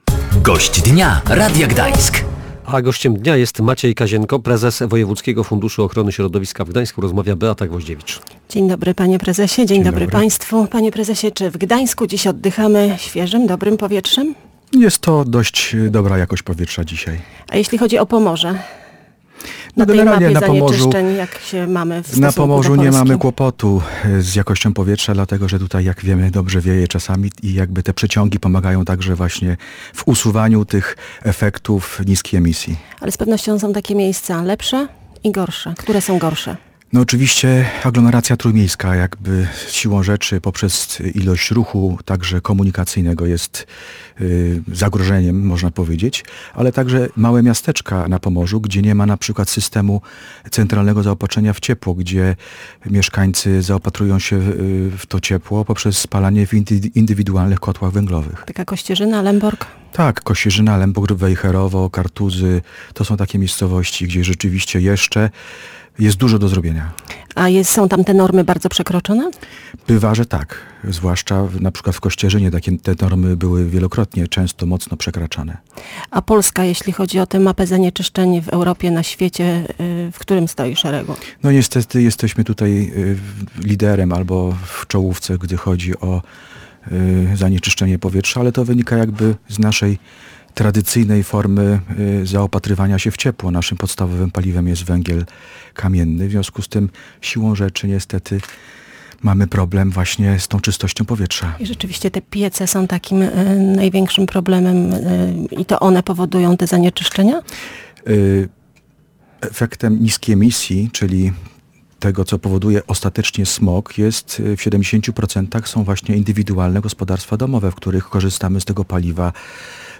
Gość Dnia